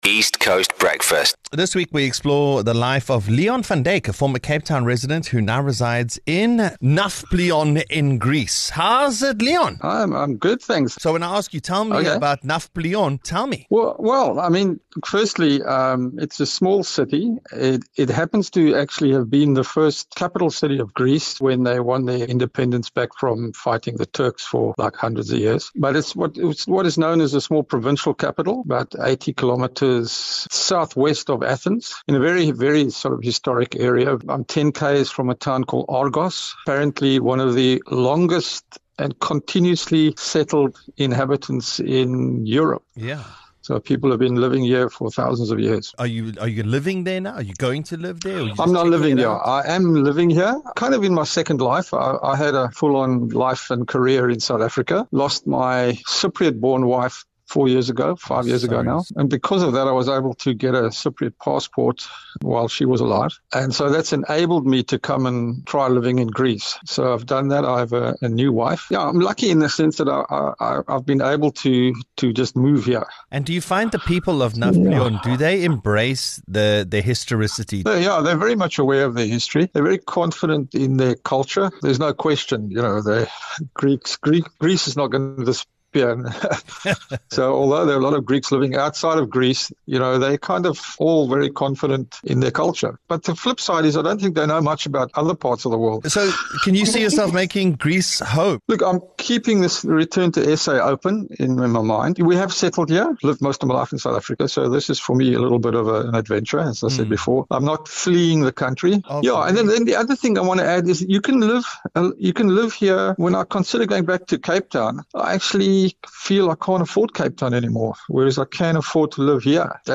The East Coast Radio Breakfast Show is a fun, and hyperlocal radio show that will captivate and entertain you.